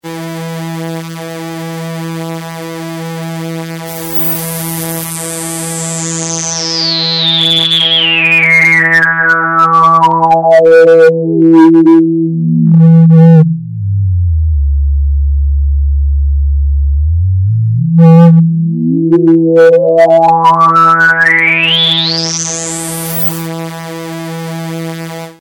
Basic_String+maxCutoff-abnehmendResonance.mp3